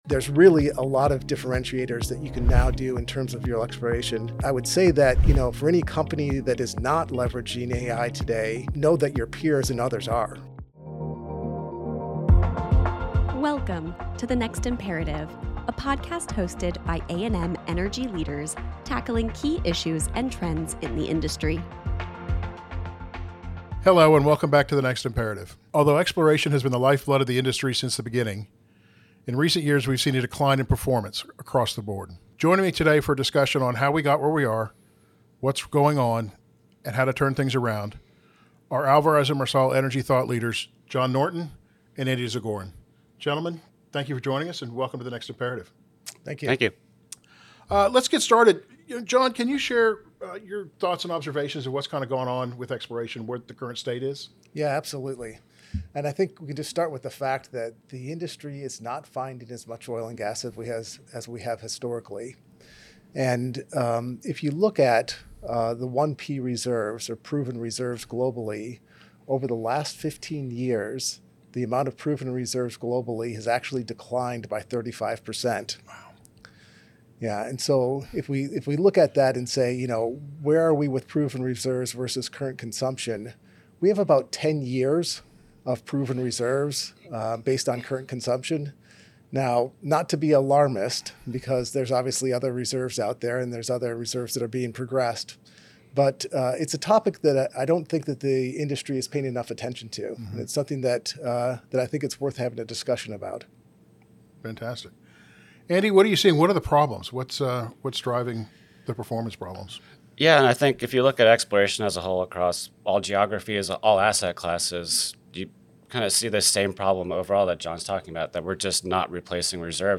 They discuss why exploration success rates have dropped sharply over the past 15 years, how financial pressures and underinvestment have created a skills gap, and what companies can do to reverse the trend. The conversation also explores how data, AI, and cross-industry lessons can help revitalize exploration strategies